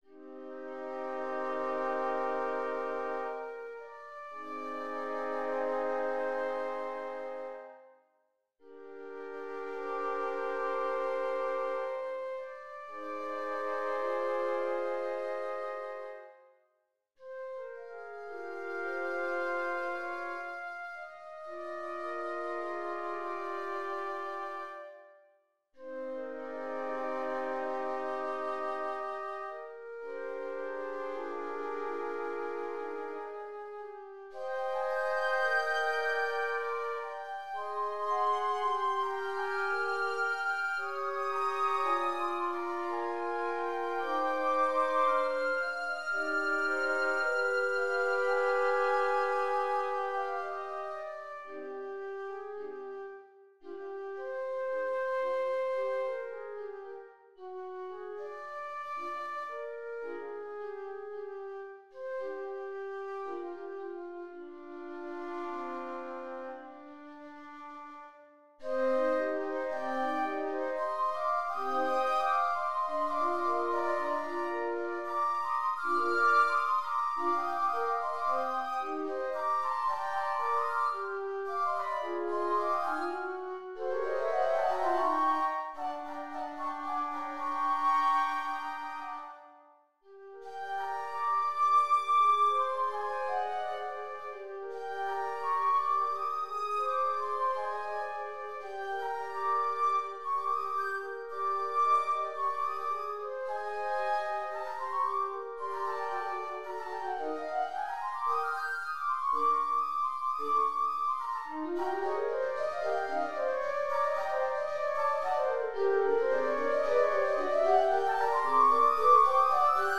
Flute Quartet for the Day of Love
Flute Quartet for the Day of Love (MIDI)